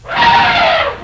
전화벨 &